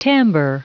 Prononciation du mot timber en anglais (fichier audio)
Prononciation du mot : timber